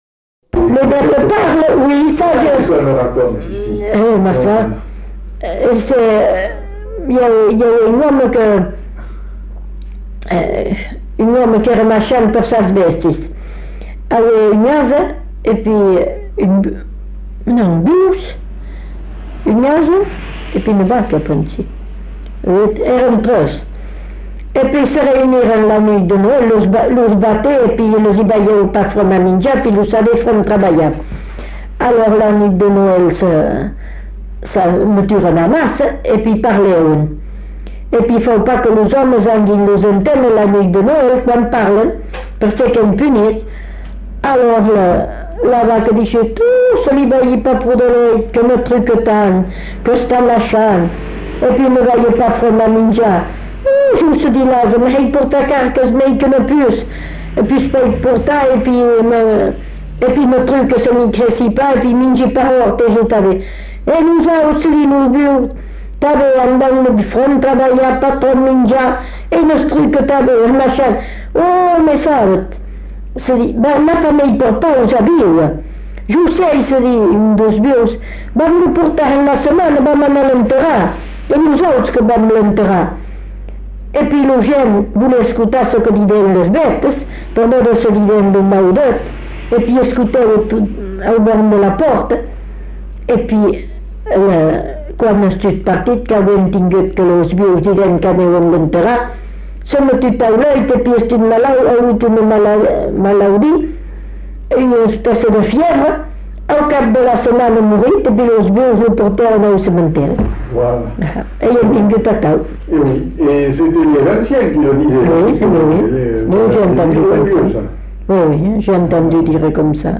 Lieu : Belin-Beliet
Genre : conte-légende-récit
Type de voix : voix de femme
Production du son : parlé
Classification : récit anecdotique